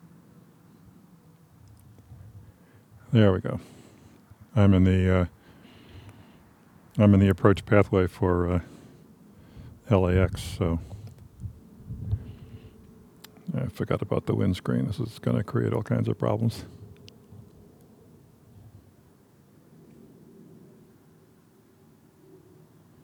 The first clip is raw capture with the volume adjusted.
I didn’t do anything out of the ordinary except tell the Zoom recorder to pay attention to the external microphones instead of its own built-in ones.
Clip-1 has some rumble and thump noises missing from Clip-2.
I’m standing outside for this capture.